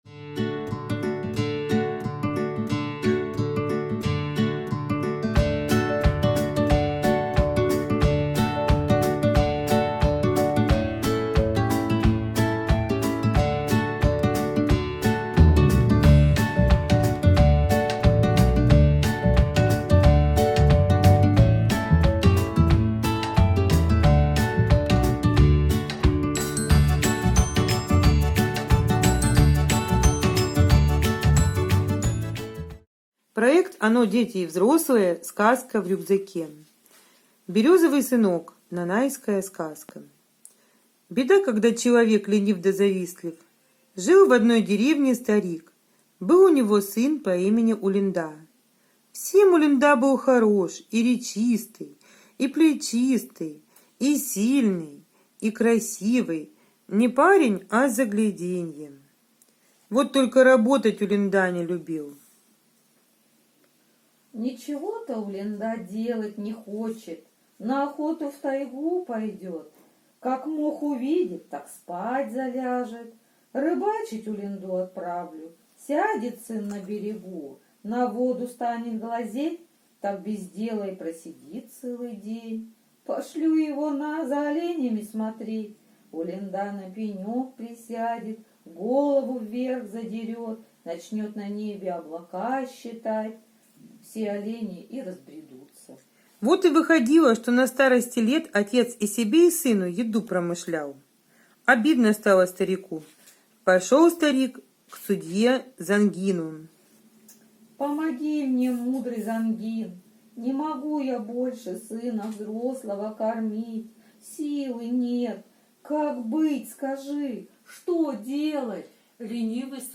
Березовый сынок - нанайская аудиосказка. Сказка про старика, его ленивого сына и сыночка, которого он под срубленной березой нашел.